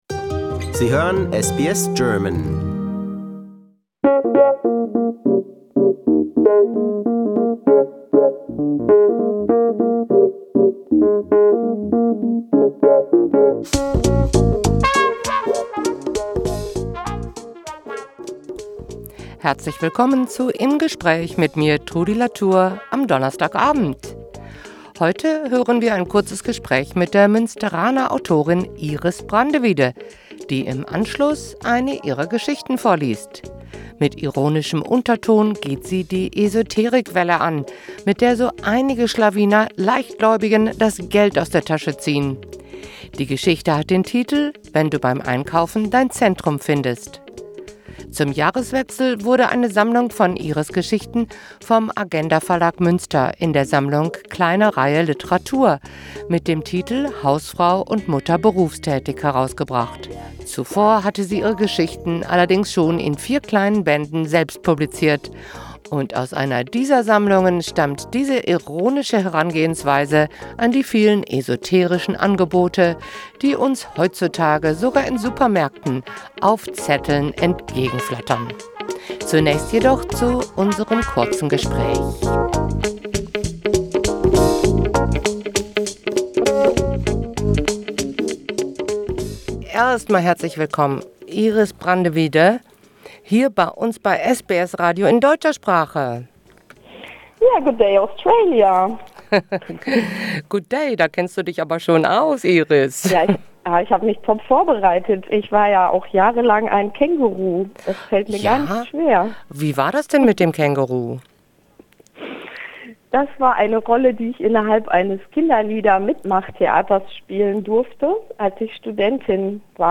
Das Geschäft mit der Esoterik: Im Gespräch